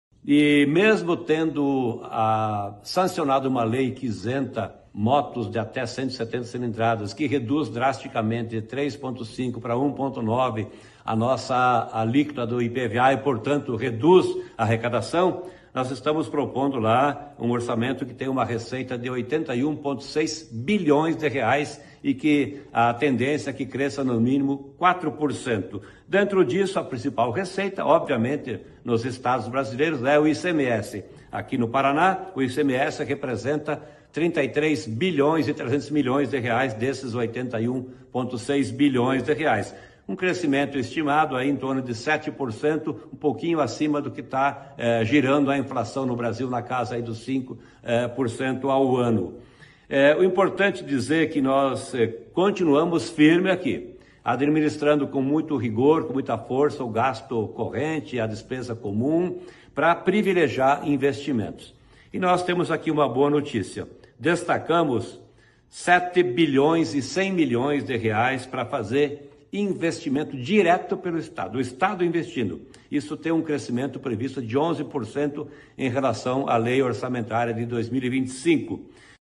Sonora do secretário da Fazenda, Norberto Ortigara, sobre a Lei Orçamentária Anual de 2026